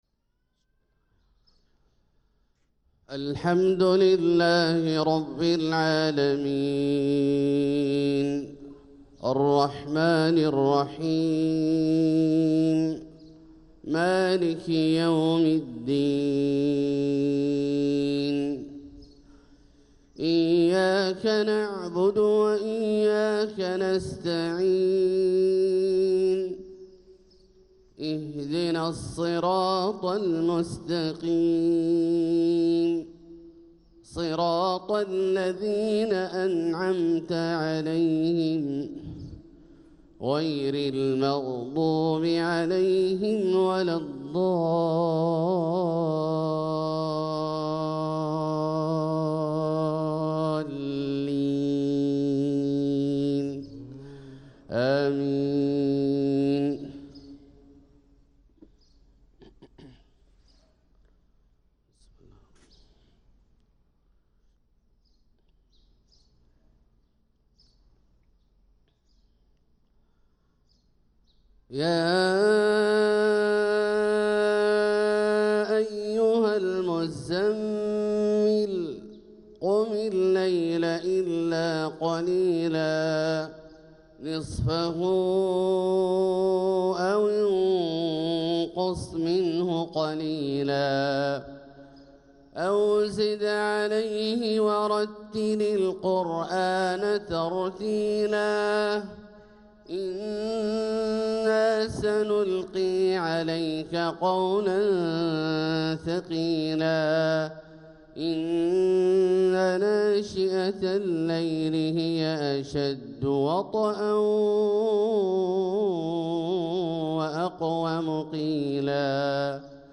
صلاة الفجر للقارئ عبدالله الجهني 28 جمادي الأول 1446 هـ
تِلَاوَات الْحَرَمَيْن .